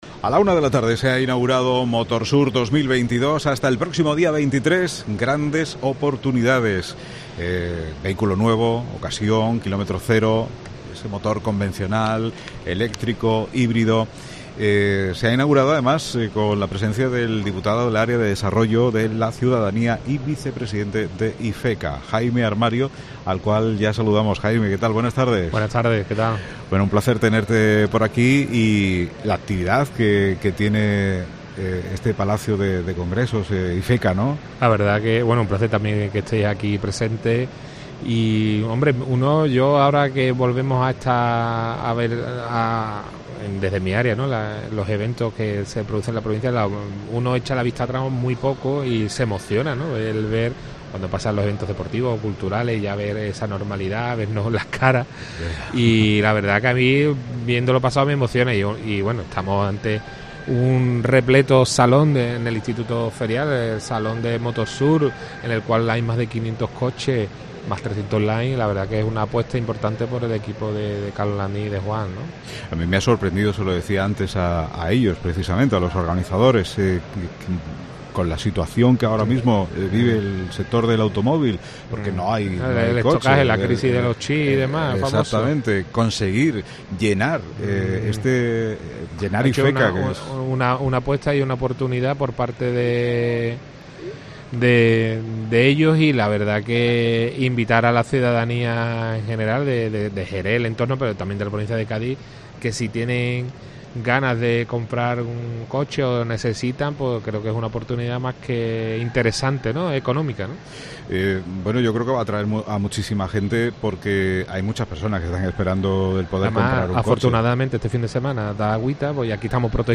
Escucha aquí a Jaime Armario, Diputado del Área de Desarrollo de la ciudadanía y Vicepresidente de IFECA hablando de MOTORSUR 2022: